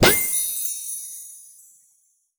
magic_pop_open_04.wav